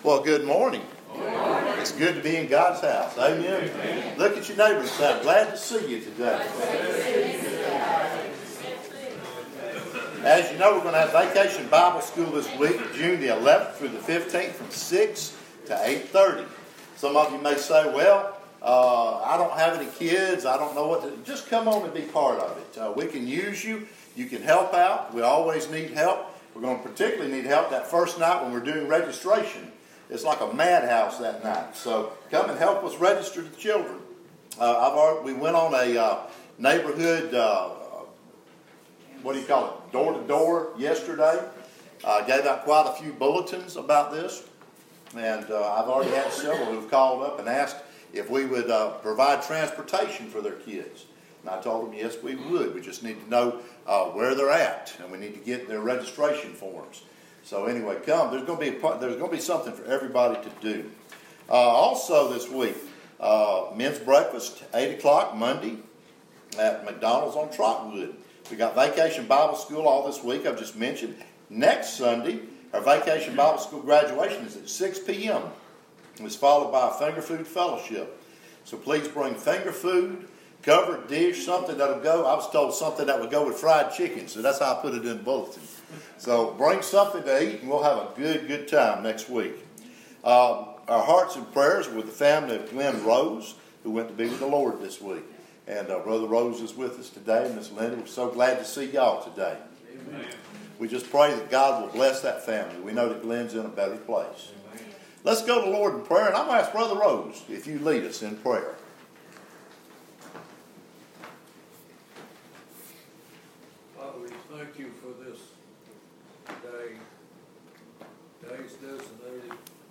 Making Daniels – a Vacation Bible School Message